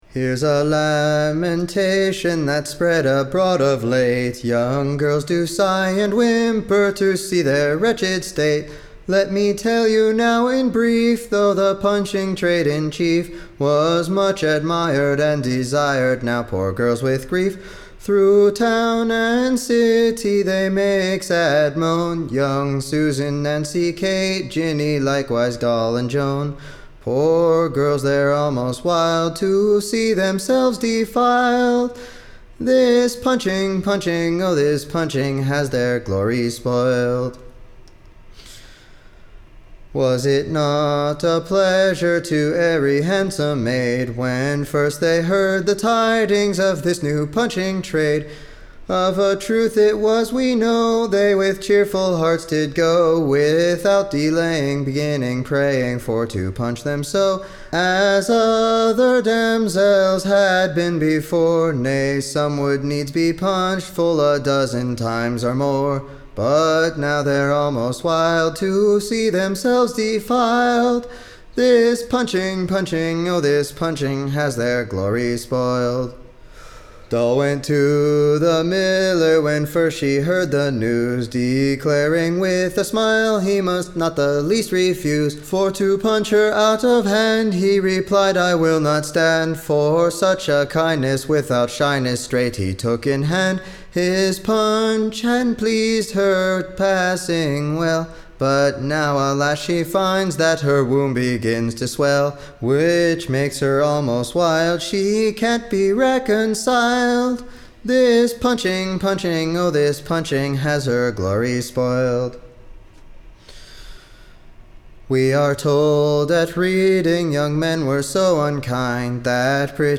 Recording Information Ballad Title THE / Young Damsels Lamentation: / OR, THEIR / Dreadful Outcry against the late Punching, / WHICH / Has crack'd above four hundred and fifty West-country Maiden-heads. Tune Imprint To the Tune of, The Scotch Hay-makers.